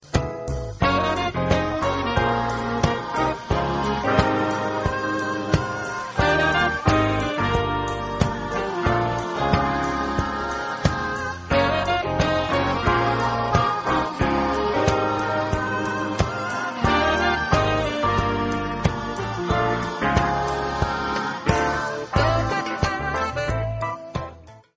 un titre soul